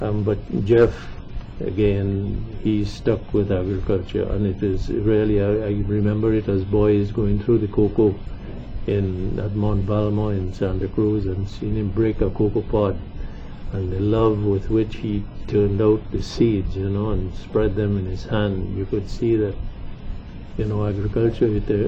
This interview in which Gerry Gomez talks about Jeff Stollmeyer was done following the tragic death of Jeff Stollmeyer who was scheduled to be interviewed as part of the series on Cricket.
1 audio cassette